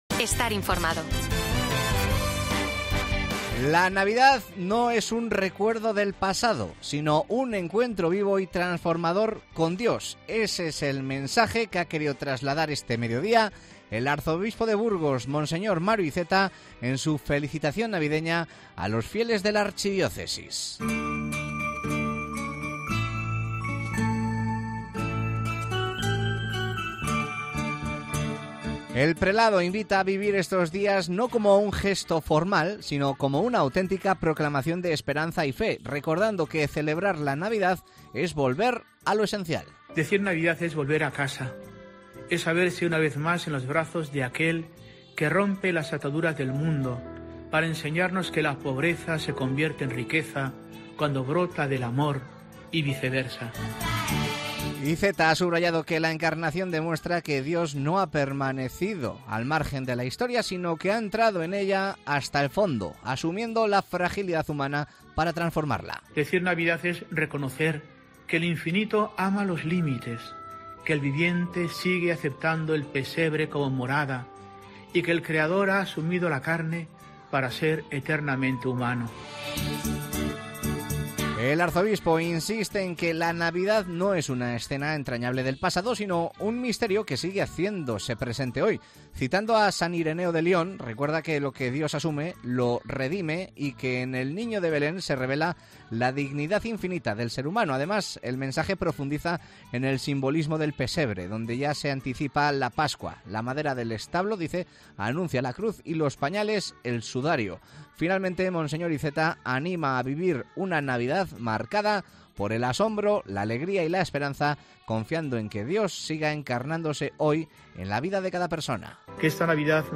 El arzobispo de Burgos subraya en su mensaje navideño que la Encarnación es un misterio actual que sana la condición humana y la dignifica